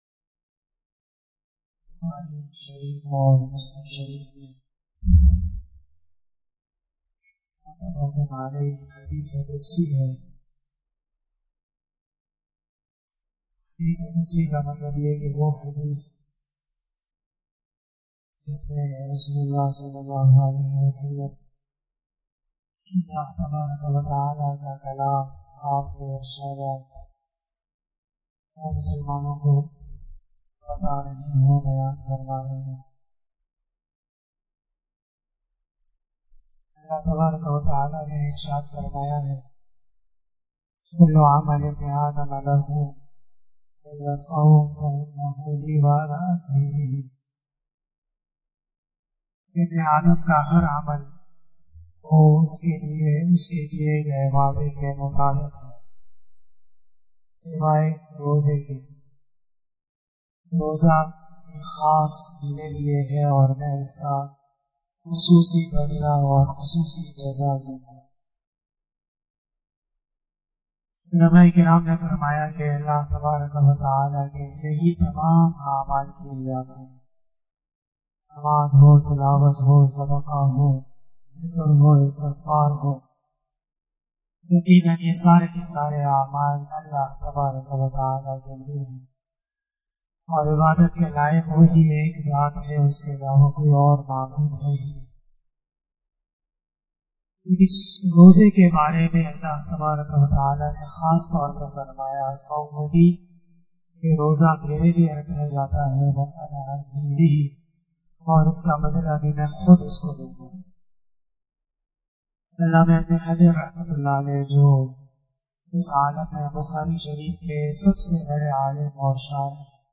016 After Asar Namaz Bayan 28 April 2021 ( 15 Ramadan 1442HJ) Wednesday